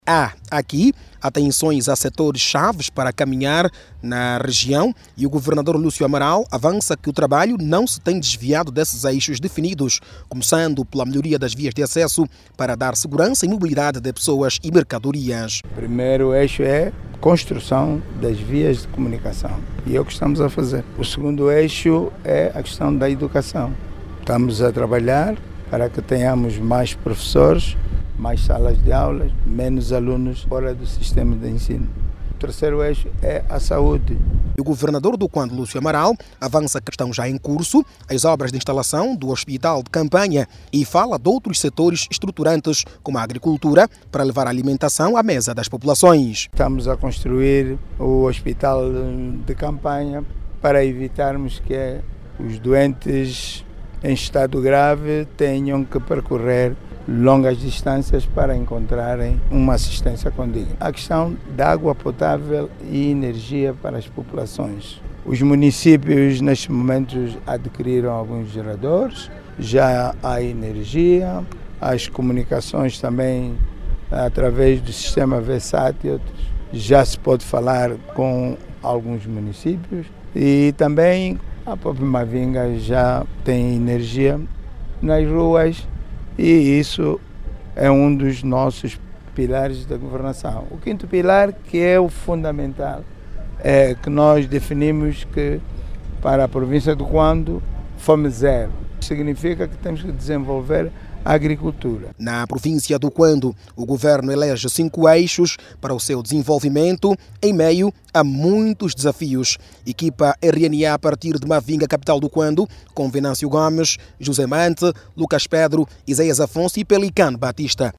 A melhoria das vias de comunicação, escolas e saúde são as prioridades do governo local. Jornalista